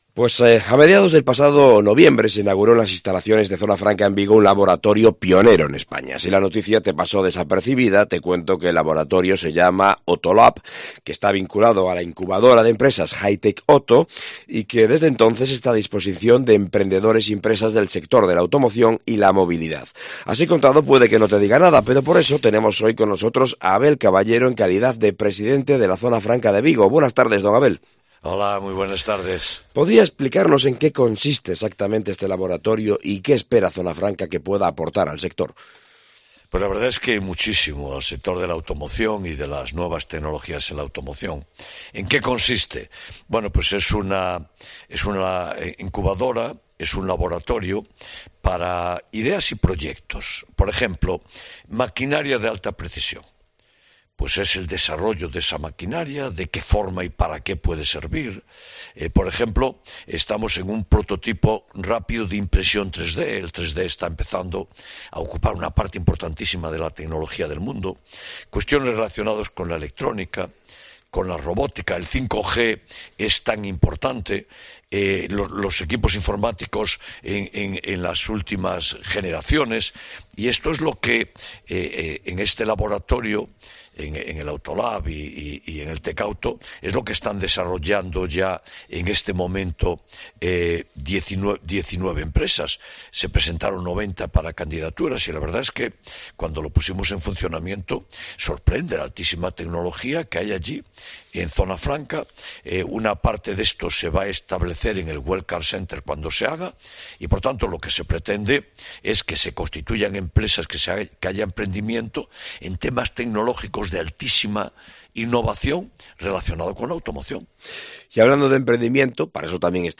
Entrevista con Abel Caballero, presidente de Zona Franca de Vigo